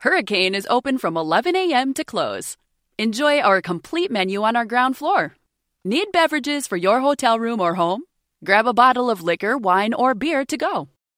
Restaurant Audio Ad Sample